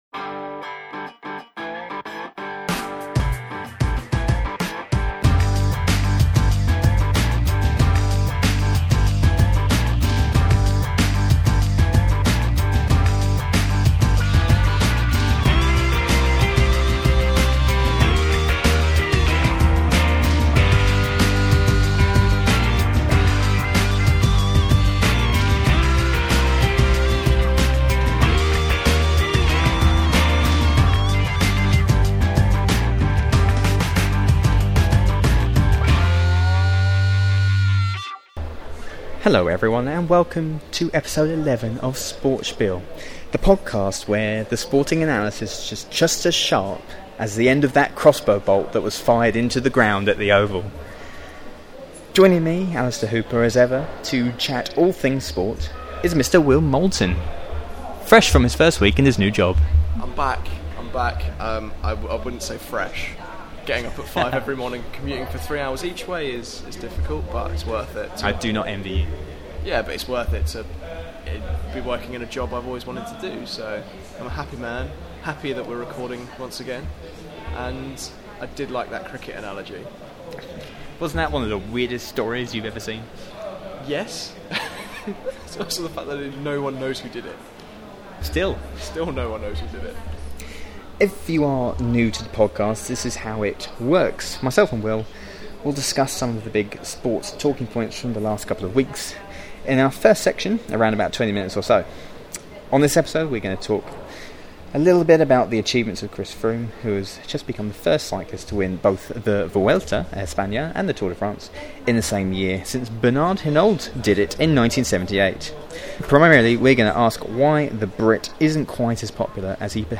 Should Russia be banned from the 2018 PyeongChang Winter Olympics? Our feature interview segment in this episode is with British sprinter Reece Prescod who shot into the public eye with his seventh-placed finish in the 100m at the London 2017 World Championships.